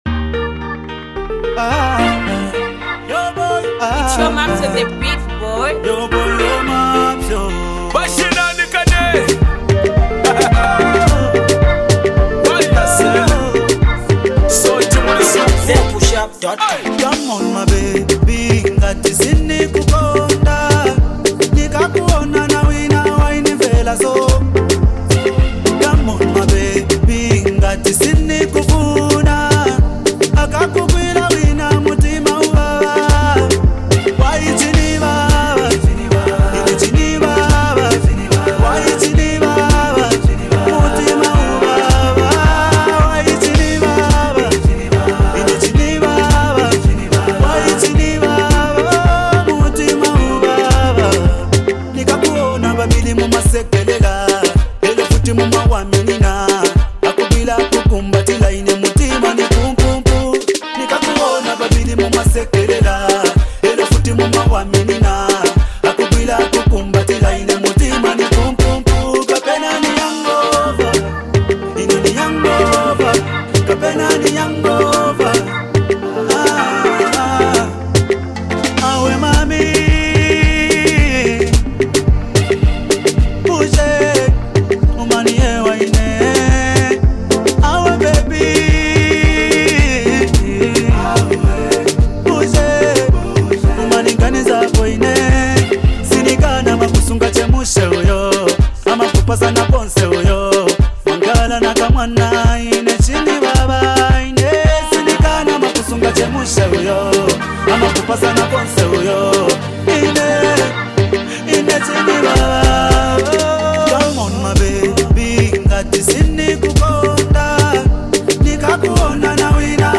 emotional joint